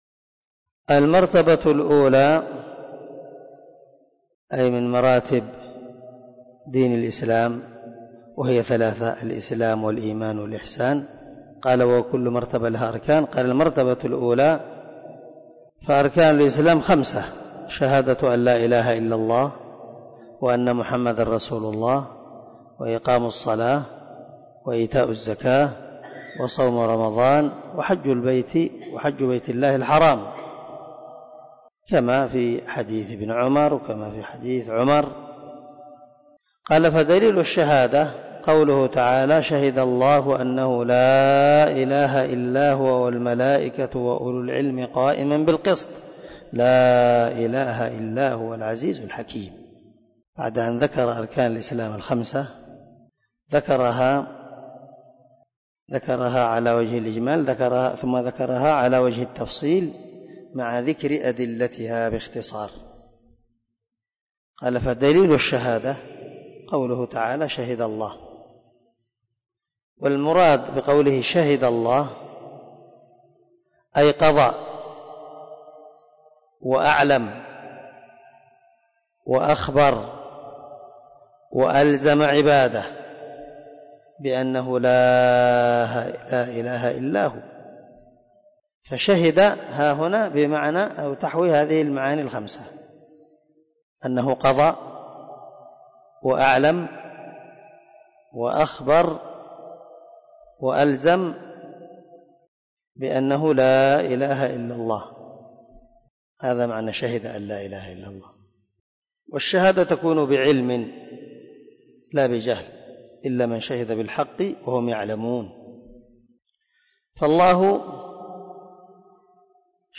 🔊 الدرس 23 من شرح الأصول الثلاثة
الدرس-23-المرتبة-الأولى-الإسلام.mp3